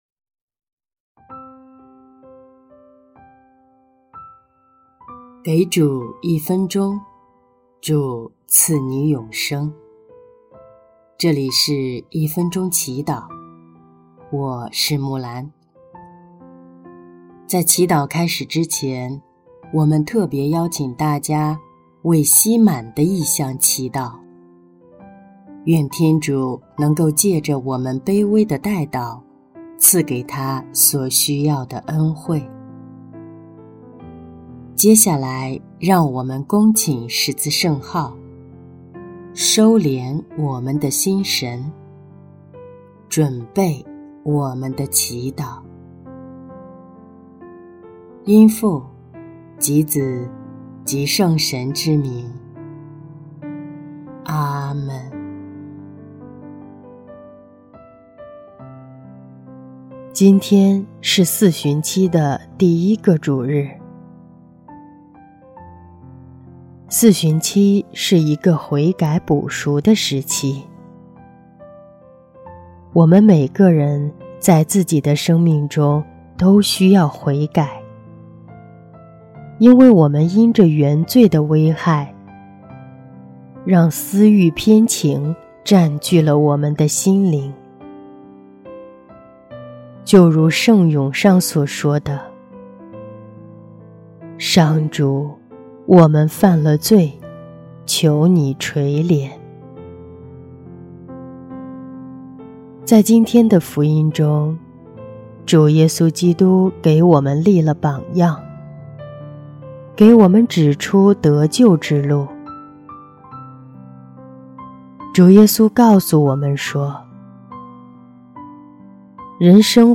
音乐： 第三届华语圣歌大赛获奖歌曲《我众罪人》